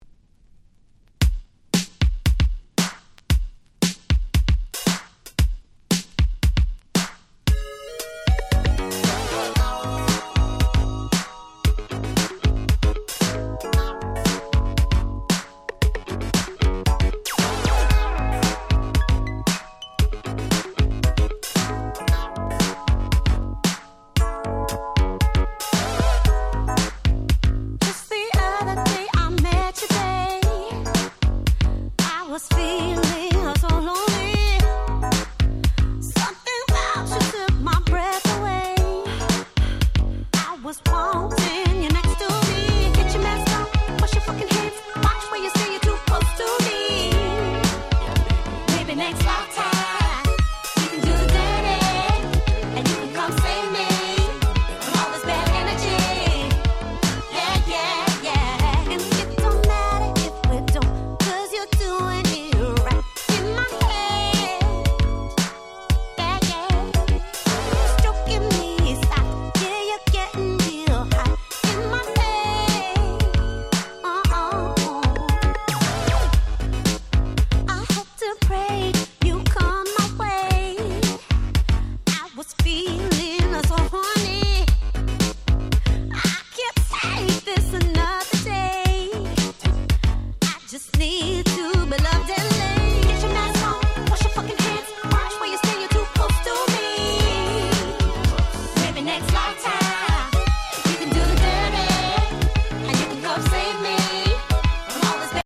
煌びやかな女性Vocalが心地良い爽やかBoogie